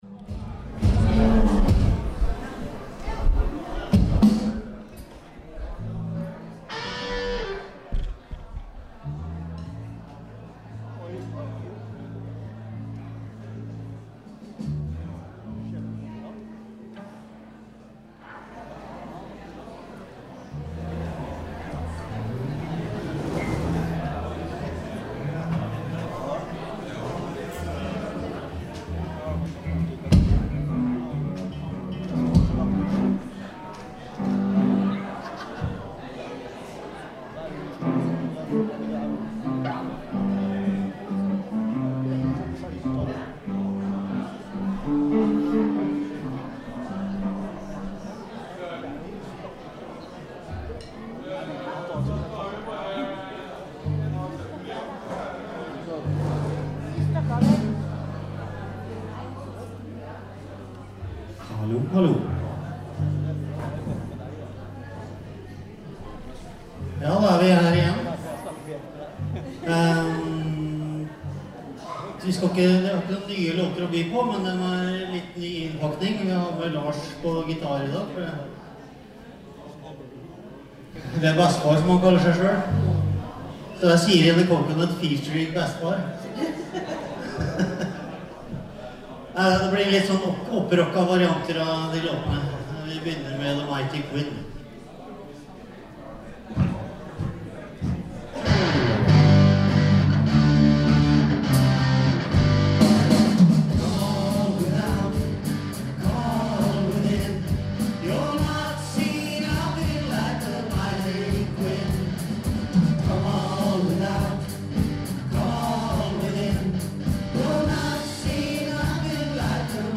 I dag hadde vi igjen gleden av å spille på Byparken, Halden.